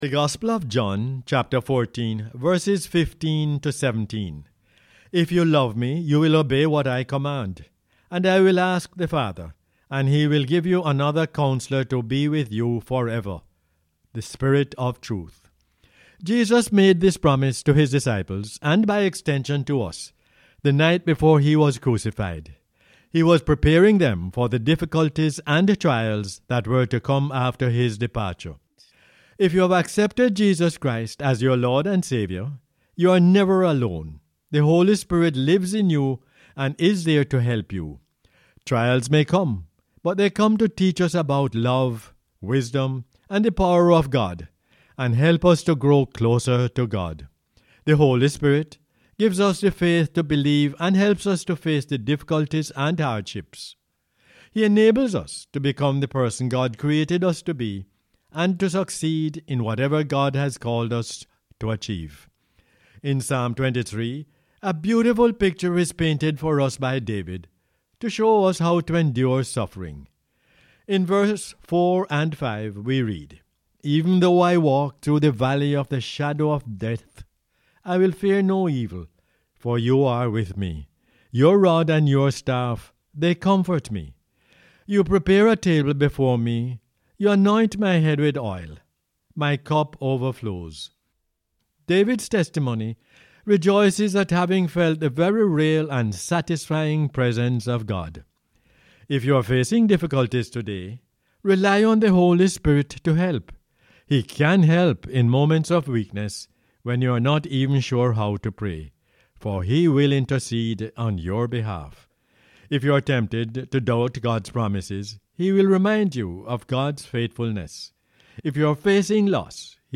John 14:15-17 is the "Word For Jamaica" as aired on the radio on 21 August 2020.